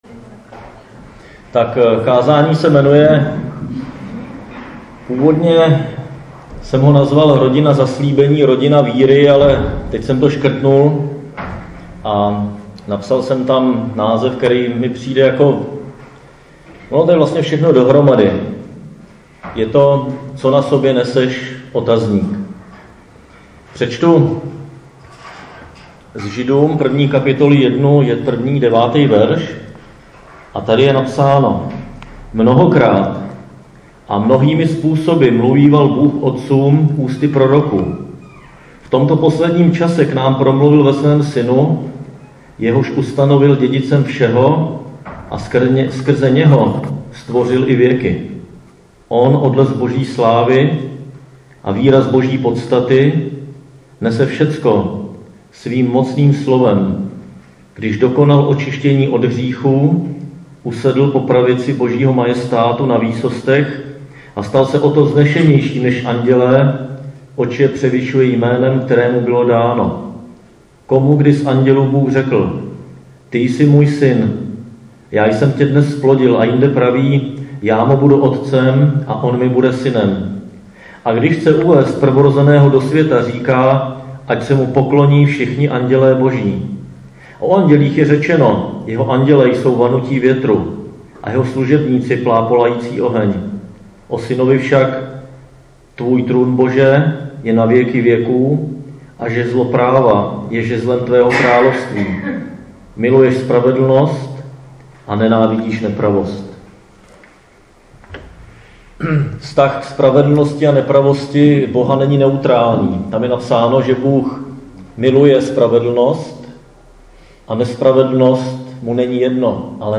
Křesťanské společenství Jičín - Kázání 16.7.2017